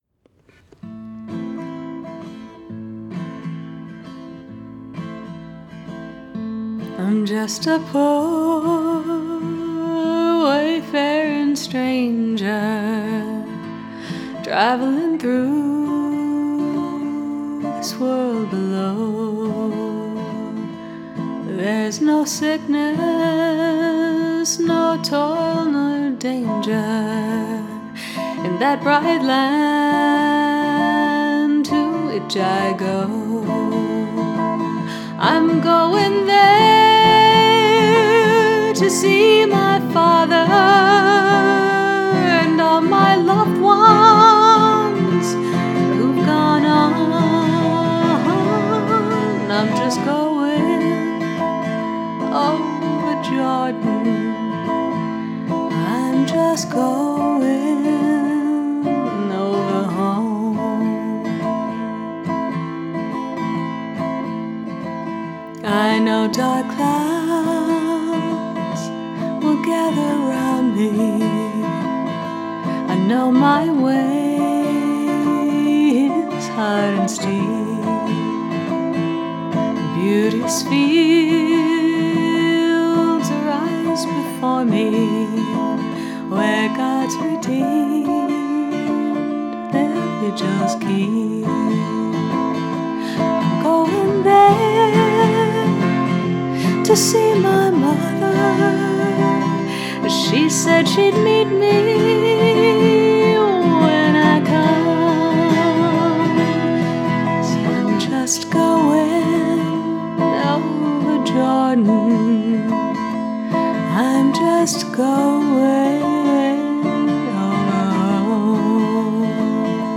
music that reflected the ‘unvarnished first-take’ quality
Over Jordan, performed without warm-up, with my oldest kiddo
I flipped on the studio power, sat in front of the mics; he stood, guitar stuck just inside the studio door (it’s 4×4 – no room in there for both of us), and we did a rehearsal take.